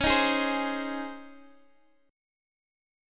C#M7sus2 chord